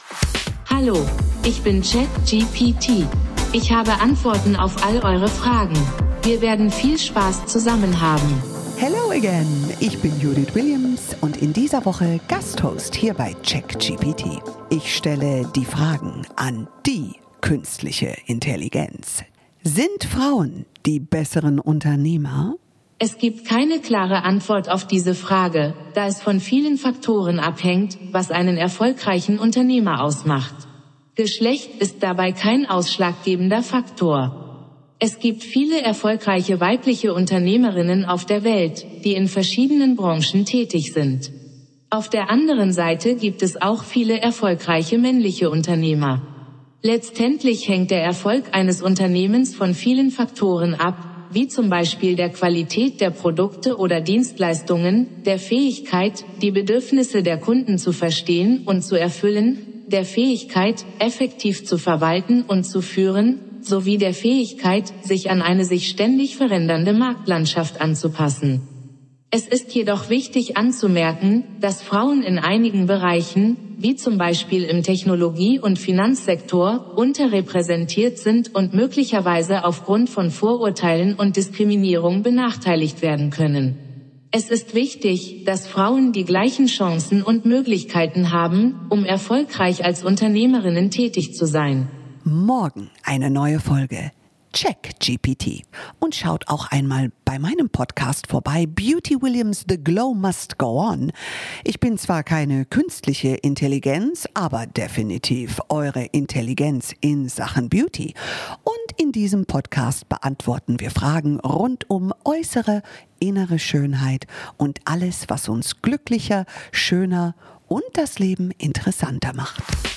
Judith Williams & KI
Finale für Gast-Moderatorin Judith Williams: Eine Woche lang stellt